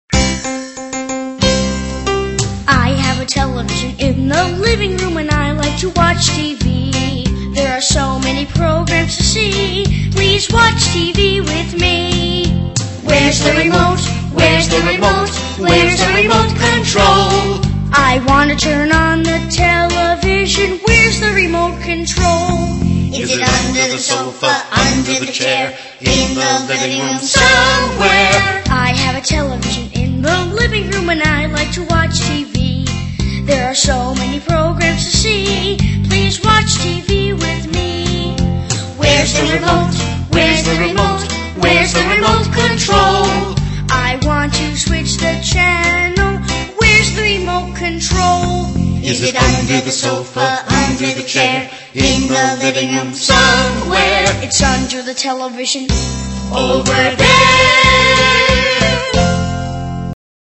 在线英语听力室英语儿歌274首 第81期:I have a television的听力文件下载,收录了274首发音地道纯正，音乐节奏活泼动人的英文儿歌，从小培养对英语的爱好，为以后萌娃学习更多的英语知识，打下坚实的基础。